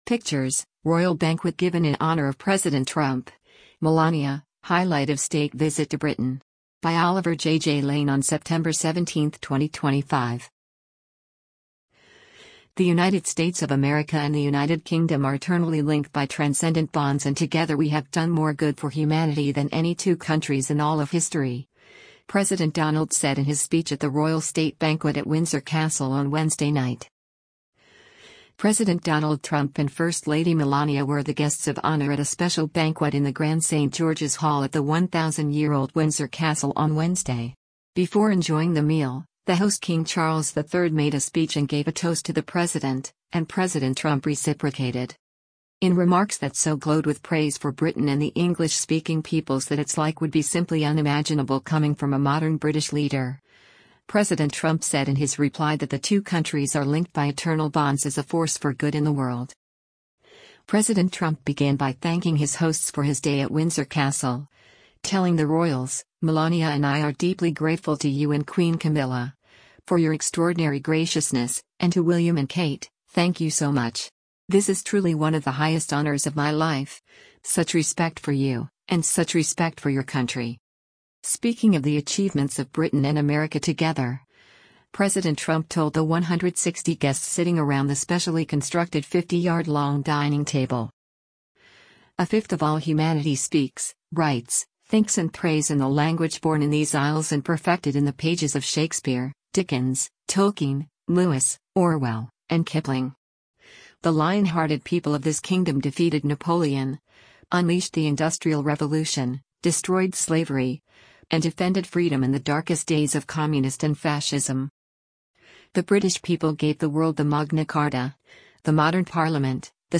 President Donald Trump and First Lady Melania were the guests of honour at a special banquet in the grand St George’s Hall at the 1,000-year-old Windsor Castle on Wednesday. Before enjoying the meal, the host King Charles III made a speech and gave a toast to the President, and President Trump reciprocated.